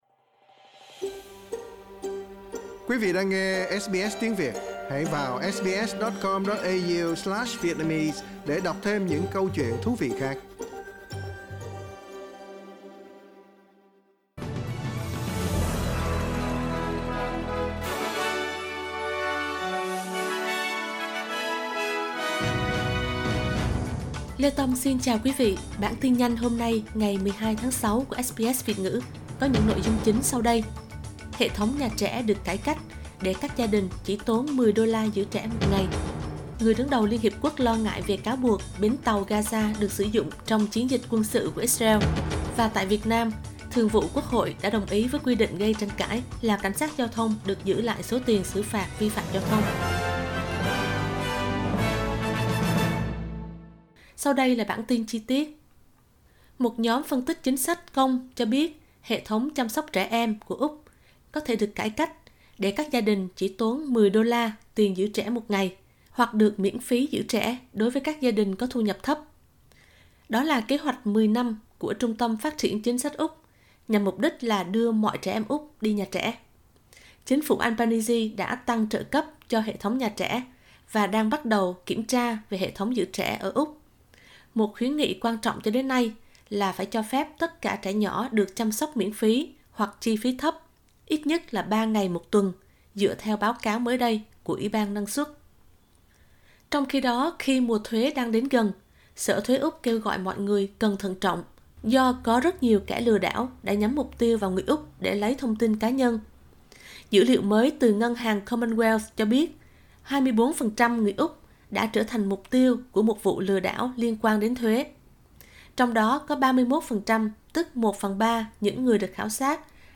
Vietnamese Bulletin